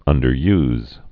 (ŭndər-yz)